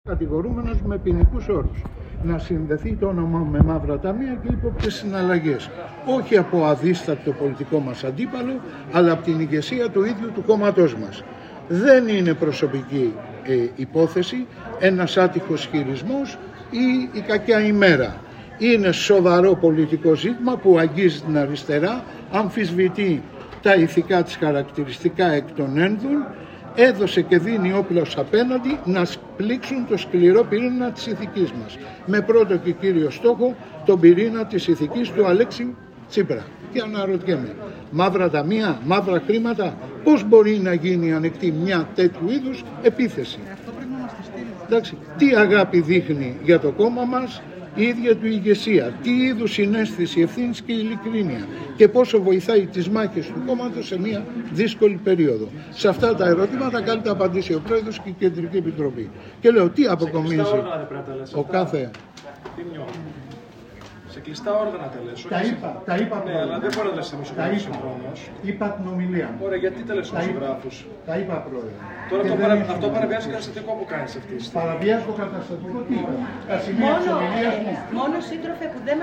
Η χαρακτηριστική στιχομυθία